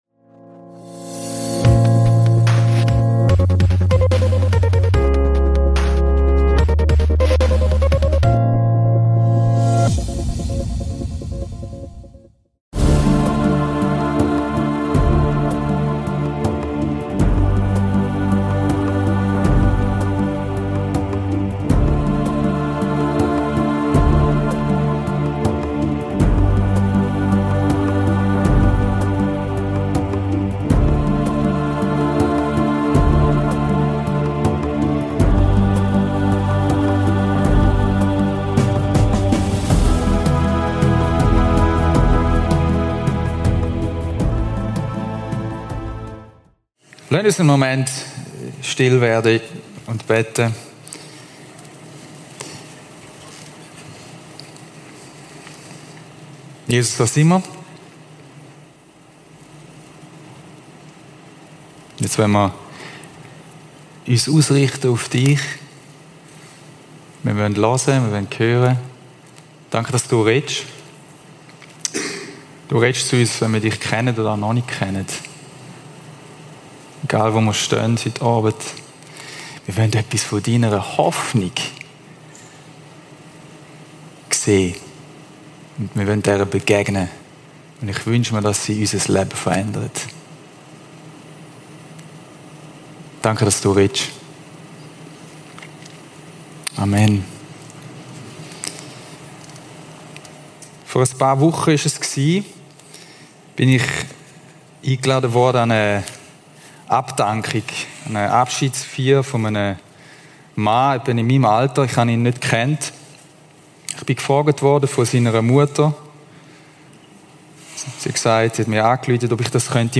Das Leben ist geprägt von Leid, Schmerz und Ungerechtigkeit. In dieser Predigt entdecken wir gemeinsam, warum wir trotz allem nicht verzweifeln müssen, sondern Grund für echte Hoffnung besteht!